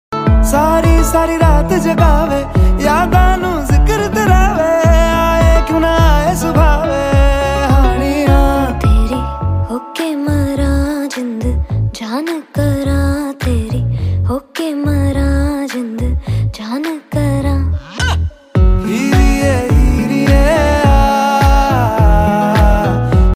soulful and enchanting vocals